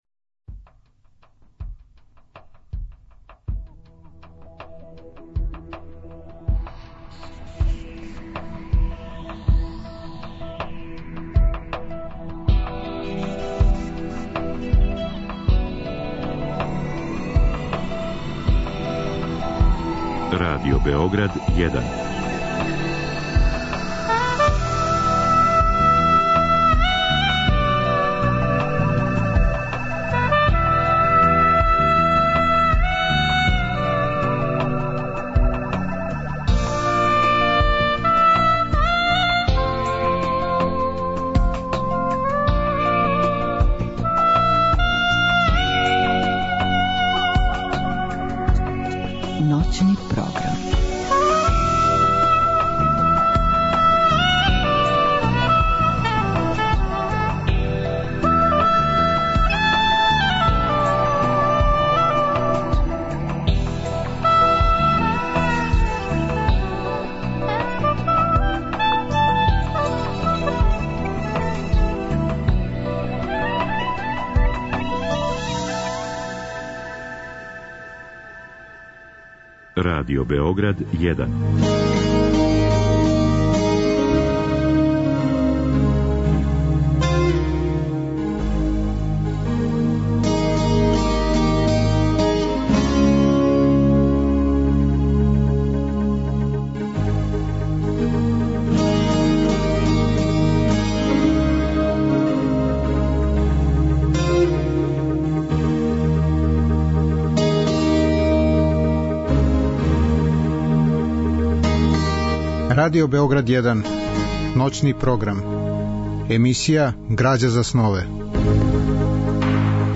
Разговор и добра музика требало би да кроз ову емисију и сами постану грађа за снове.
Слушаћемо одабране делове радио-драме Еуридика, снимљене 1961. године у режији Василија Поповића, и радио-драме Изненађење, снимљене 1985. године у режији Петра Теслића.